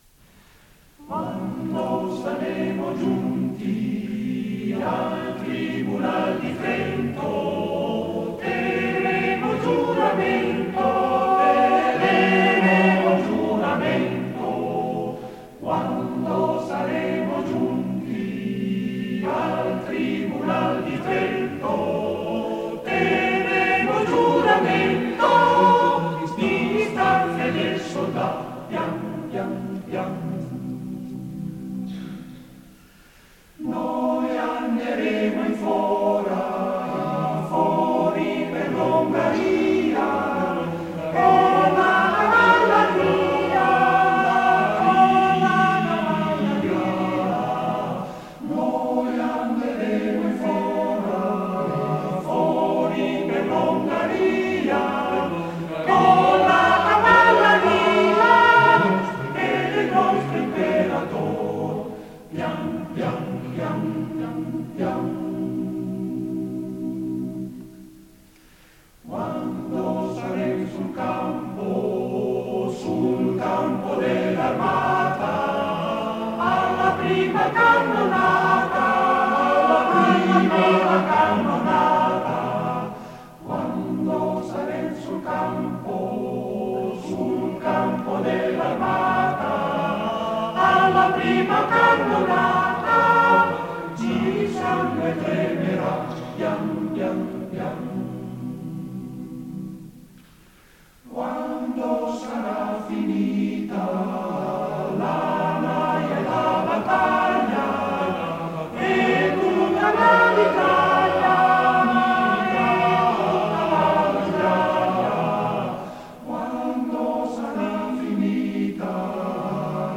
Arrangiatore: Bettinelli, Bruno (Armonizzatore)
Esecutore: Coro CAI Uget
Fa parte di: Concerto del coro Cai Uget